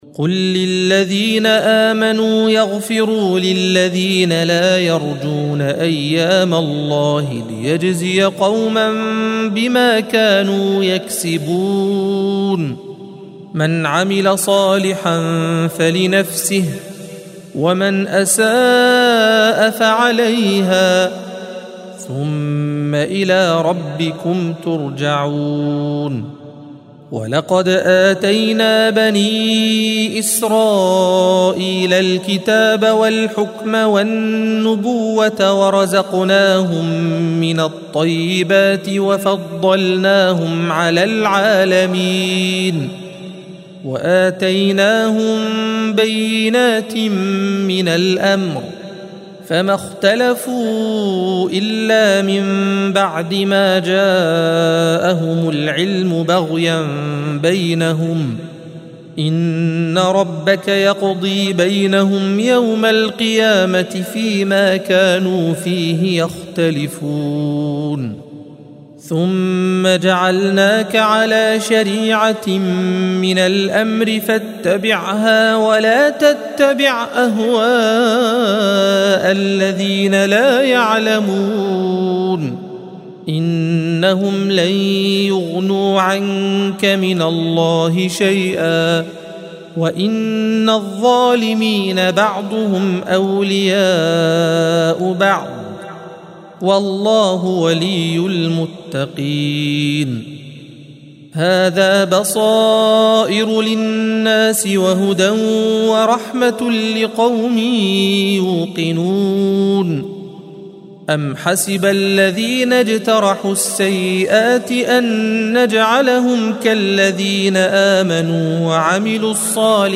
الصفحة 500 - القارئ